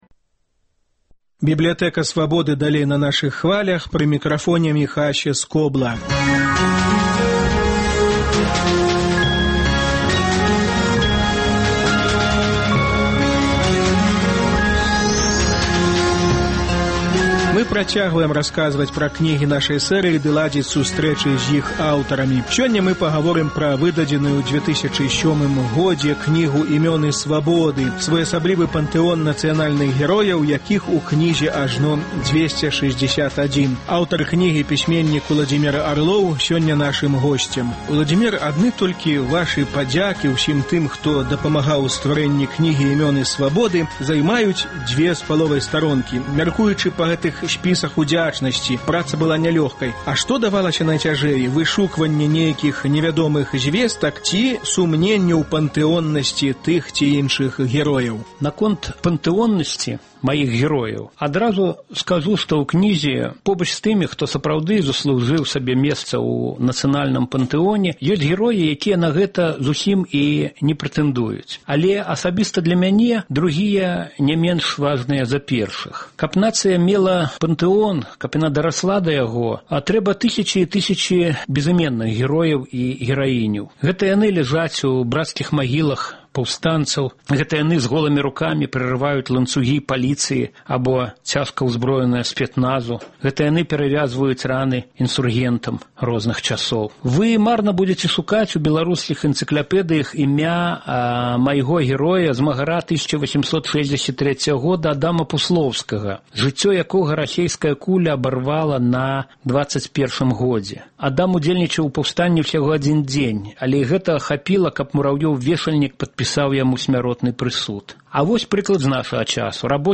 Гутарка з Уладзімерам Арловым.